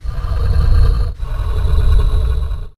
Breathing.ogg